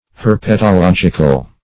Search Result for " herpetological" : The Collaborative International Dictionary of English v.0.48: Herpetologic \Her*pet`o*log"ic\, Herpetological \Her*pet`o*log"ic*al\, a. Pertaining to herpetology.
herpetological.mp3